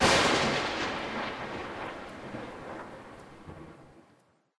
thunder3.ogg